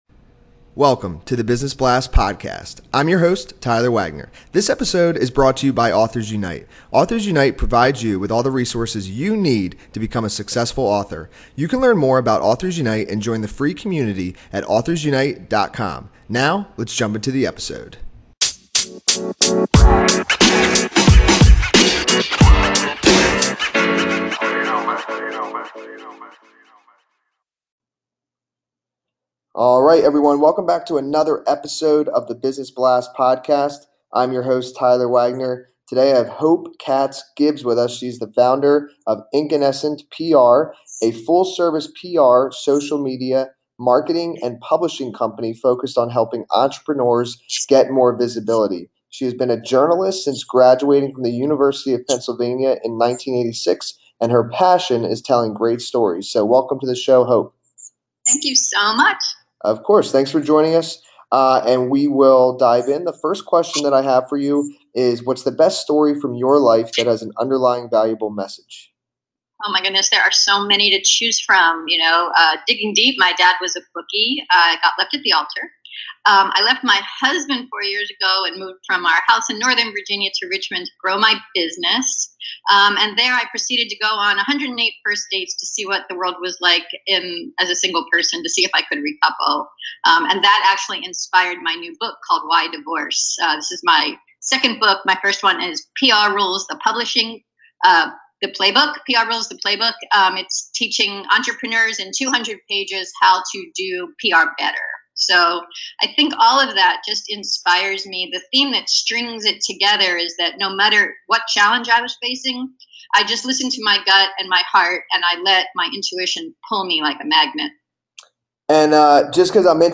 Scroll down for a transcript of our interview.